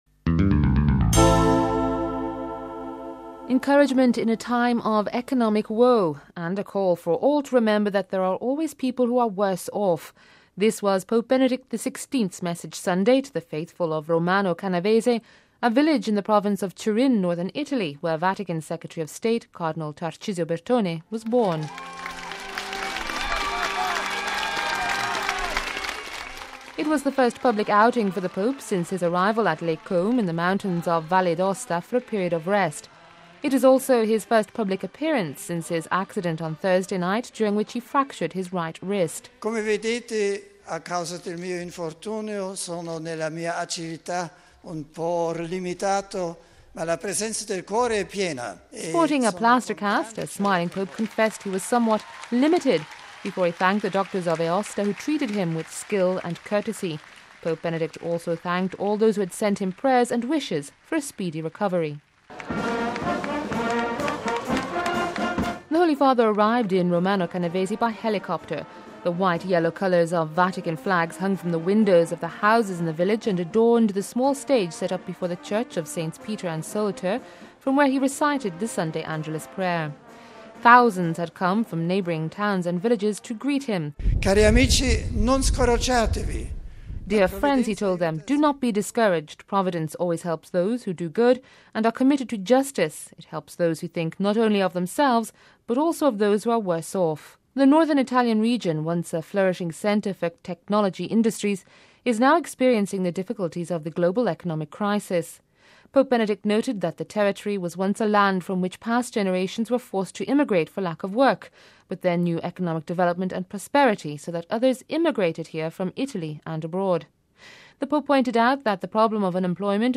The white-yellow colours of Vatican flags hung from the windows of the houses and adorned the small stage set up before the Church of Saints Peter and Soluter from where he recited the Sunday Angelus.
Thousands had come from neighbouring towns and villages to greet him.